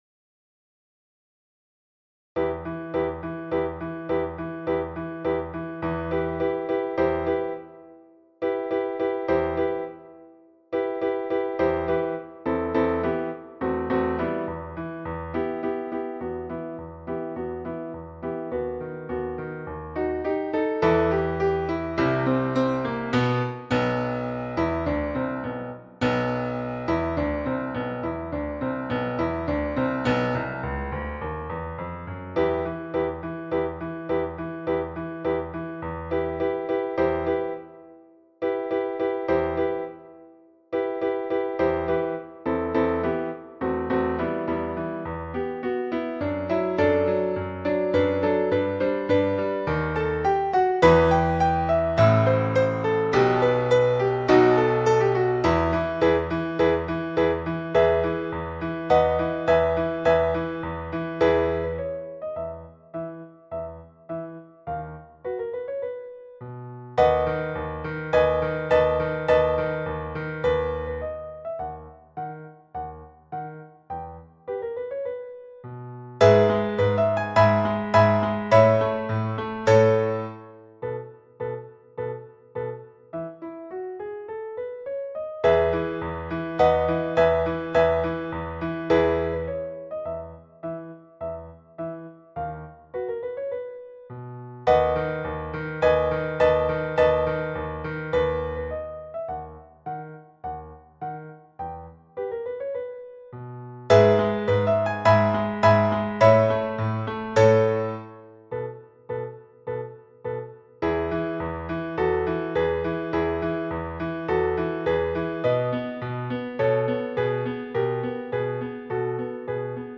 The Barber of Seville – piano à 104 bpm